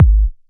edm-kick-88.wav